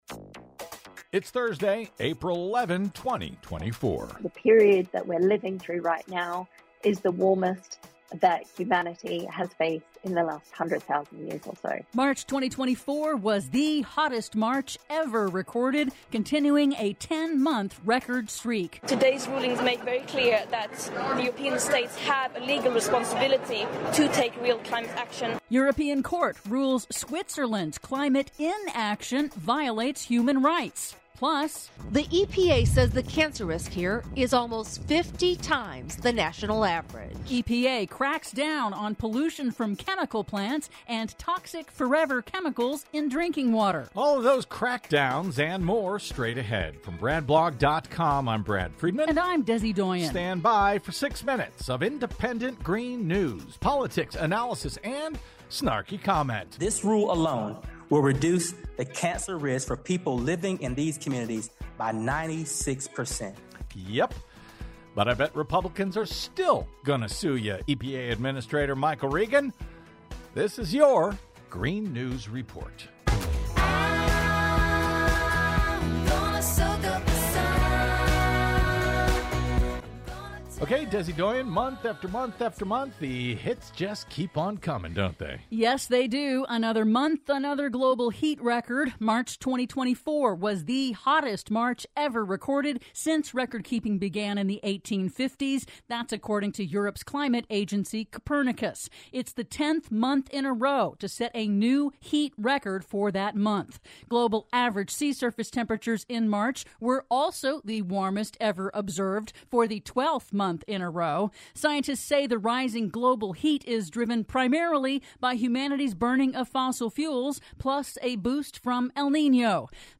IN TODAY'S RADIO REPORT: March 2024 was the hottest March ever recorded, continuing a 10-month record hot streak; European court rules Switzerland's climate inaction violates human rights; Norfolk Southern settles East Palestine lawsuits for $600 million; PLUS: EPA cracks down on airborne pollution from chemical plants and toxic 'forever chemicals' in drinking water... All that and more in today's Green News Report!